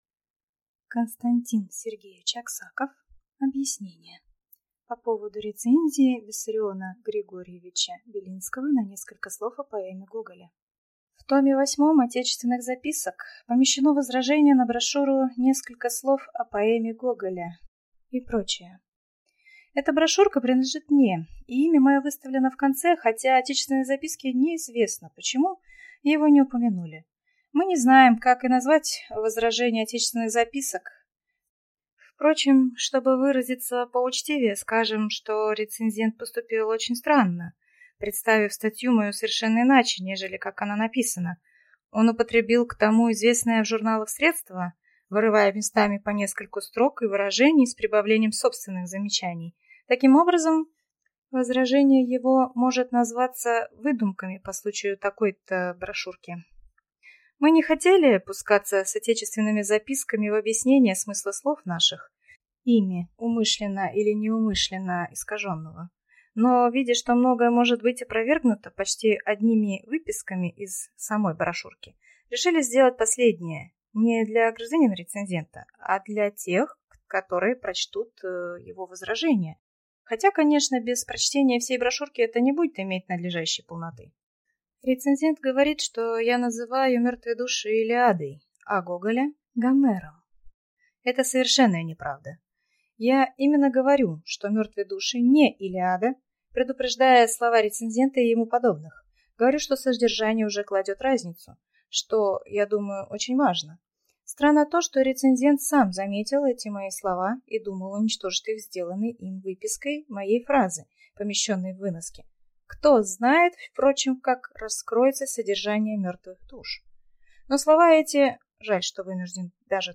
Aудиокнига Объяснение Автор Константин Сергеевич Аксаков Читает аудиокнигу
Прослушать и бесплатно скачать фрагмент аудиокниги